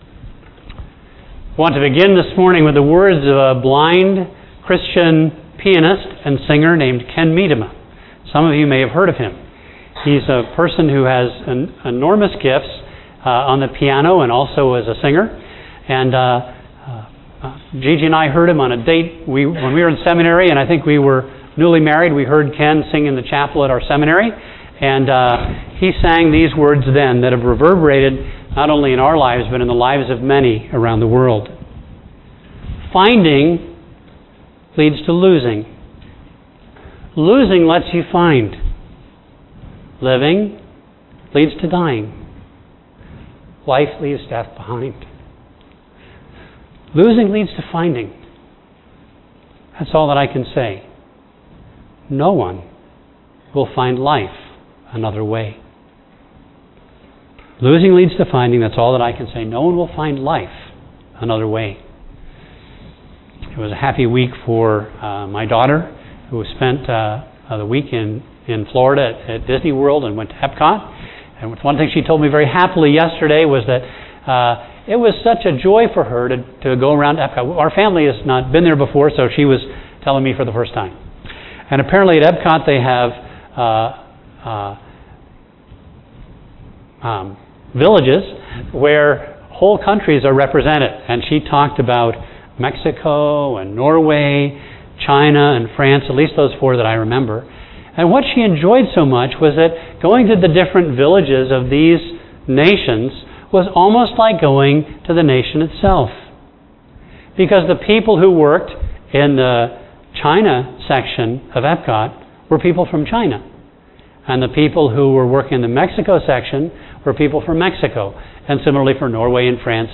A message from the series "The Acts."